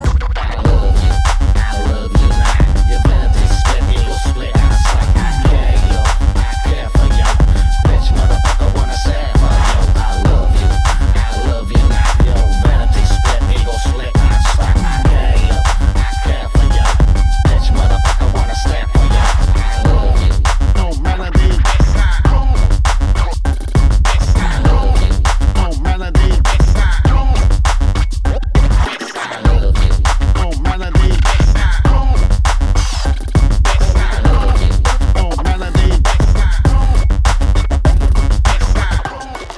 • Качество: 320, Stereo
мужской голос
Хип-хоп
Стиль: electro